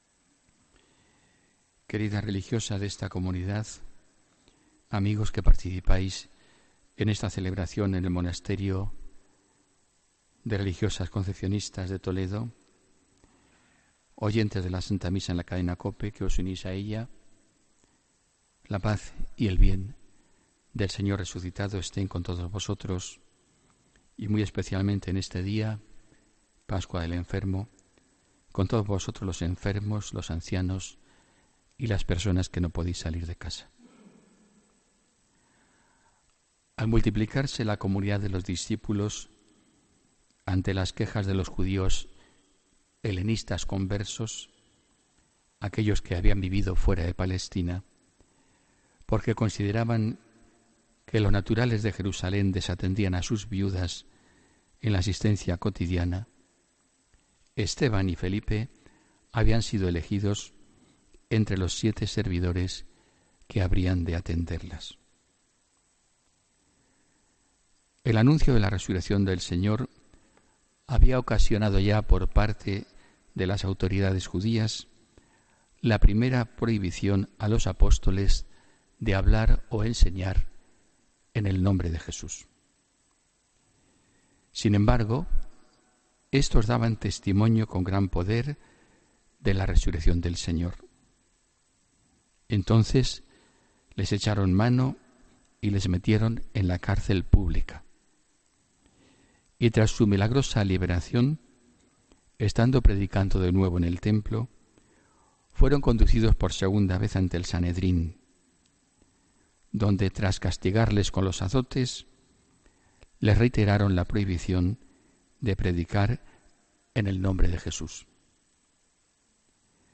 Homilía del domingo 21 de mayo de 2017